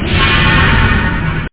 PSION CD 2 home *** CD-ROM | disk | FTP | other *** search / PSION CD 2 / PsionCDVol2.iso / Wavs / FutureSound ( .mp3 ) < prev next > Psion Voice | 1998-08-15 | 12KB | 1 channel | 8,000 sample rate | 1 second